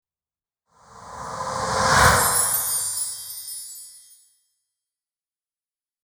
Âm thanh Xuất hiện Phụ đề rõ nét bật lên
Thể loại: Âm thanh chuyển cảnh
Description: Âm thanh Xuất hiện Phụ đề rõ nét bật lên là âm thanh chuyển cảnh cho video thêm phần thu hút, cũng có thể sử dụng trong đoạn mở đầu video để giới thiệu thông tin chính của bộ phim, những cảnh quay đắt giá cùng những phụ đề mang nội dung đúc kết của video.
Am-thanh-xuat-hien-phu-de-ro-net-bat-len-www_tiengdong_com.mp3